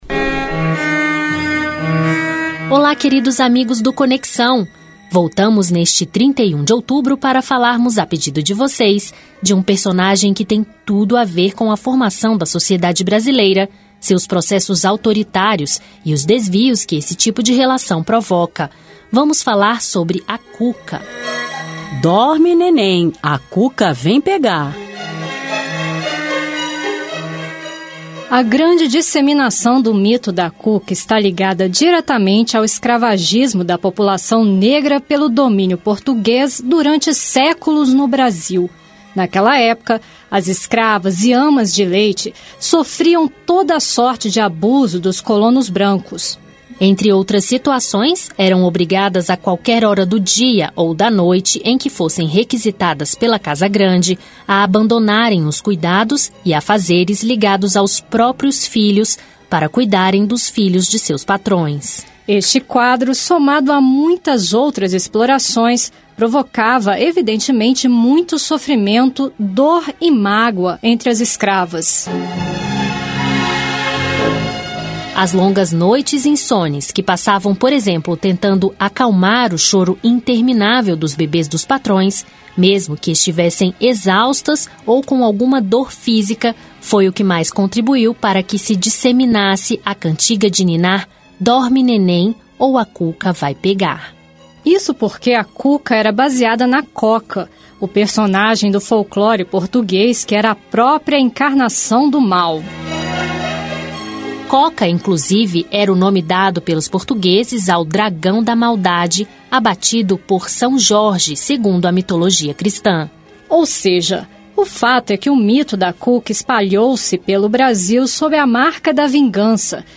Rádio Senado produz reportagens sobre o Halloween e o Folclore Brasileiro
Também concederam entrevistas sobre a relevância de se valorizar o folclore nas áreas de educação e cultura os senadores Randolfe Rodrigues (Rede-AP), José Serra (PSDB-SP), Fátima Bezerra (PT-RN), Jorge Viana (PT-AC) e João Capiberibe (PSB-AP).